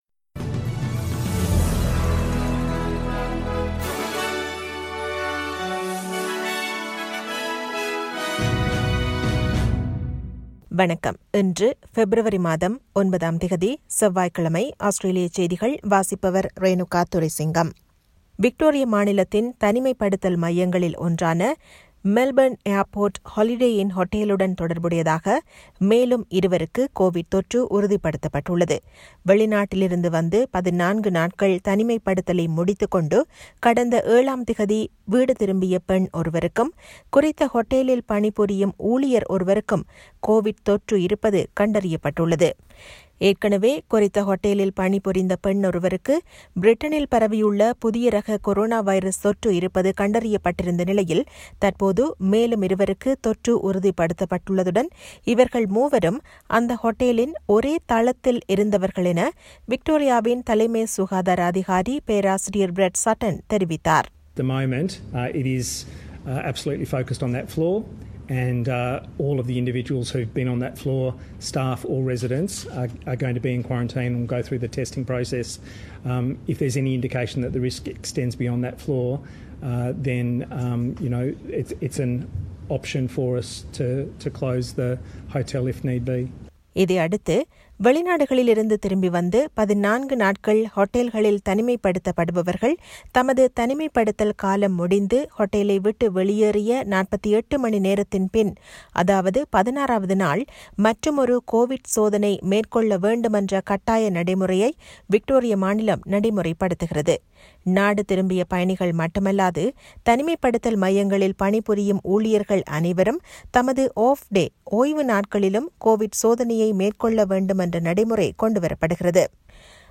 Australian news bulletin for Tuesday 09 February 2021.